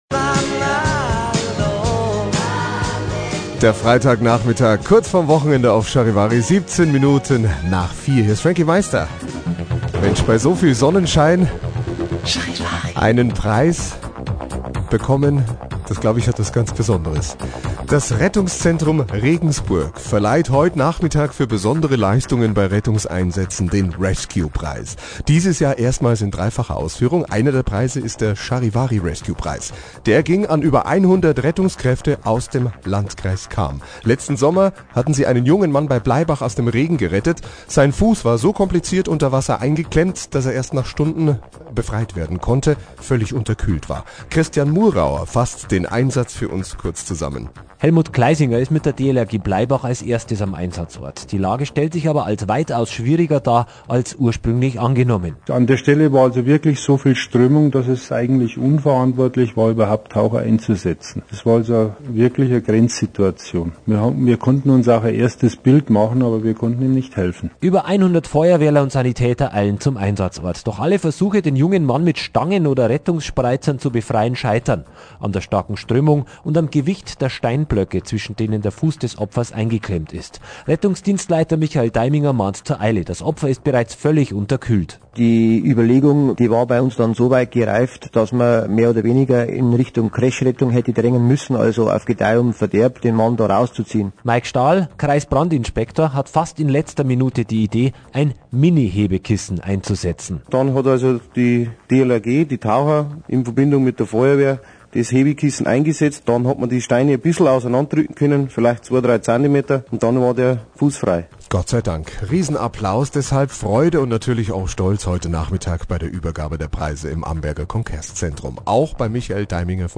Bericht nach der Preisverleihung (1,6MB MP3-File)